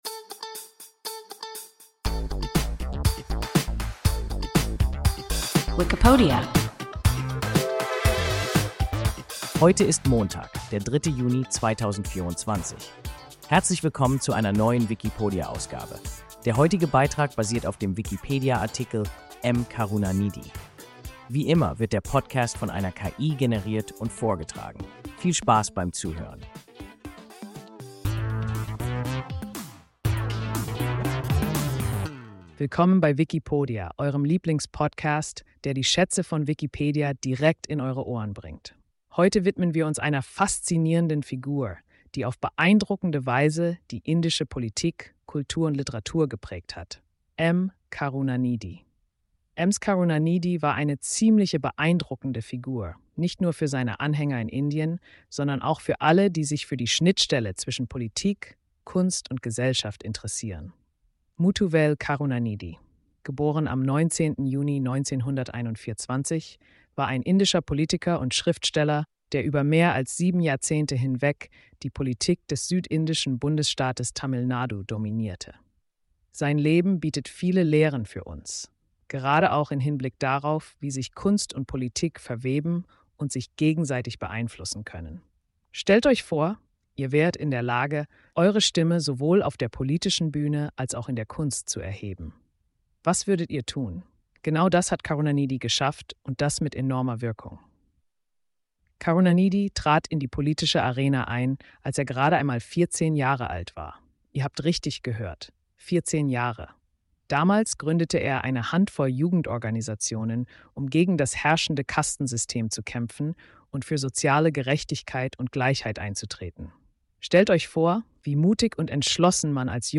M. Karunanidhi – WIKIPODIA – ein KI Podcast